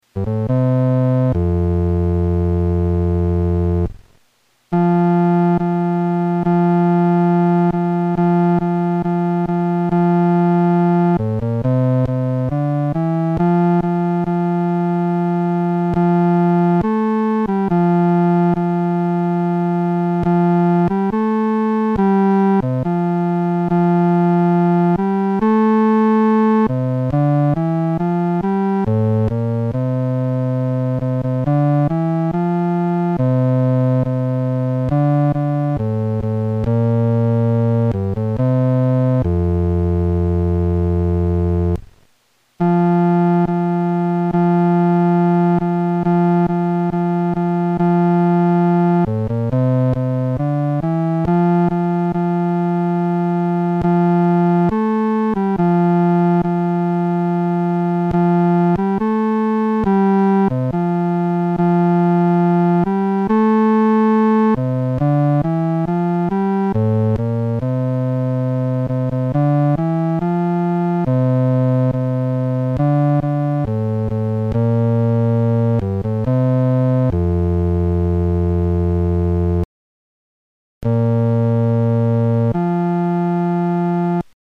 伴奏
男低
本首圣诗由网上圣诗班 （南京）录制
此曲主要刻划梅花洁白、清新的形象，曲调活泼，节奏明快，故改编者选择用它为《诗篇》第100篇所用。